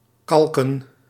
Ääntäminen
IPA : /ɹaɪt/ US